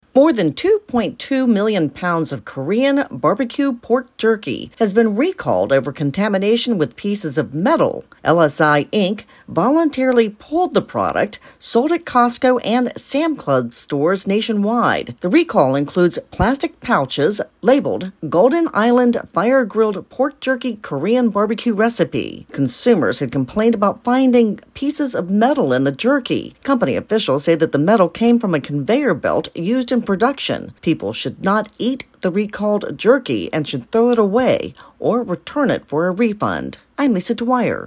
reports on a Jerky recall.